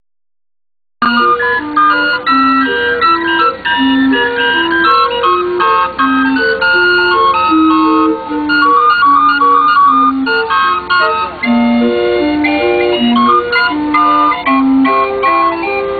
riverboat.wav